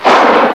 CLAP3.WAV